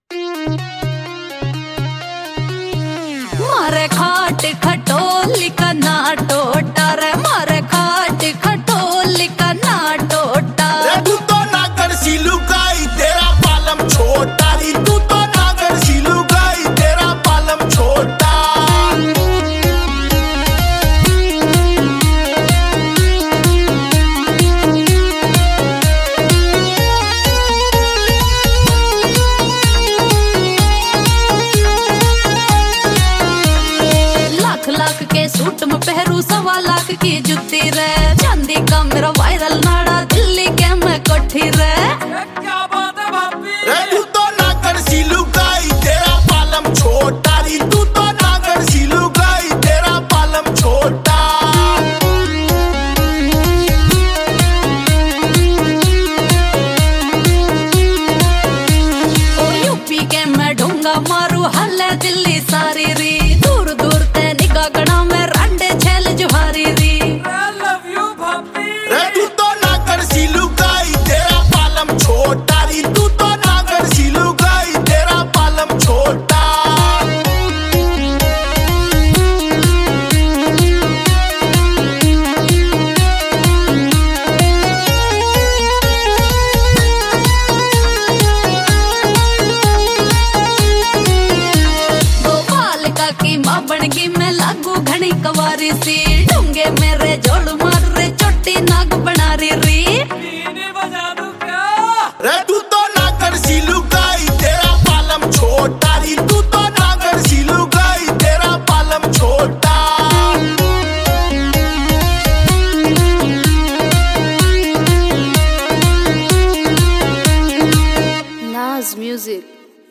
Category: Haryanvi Songs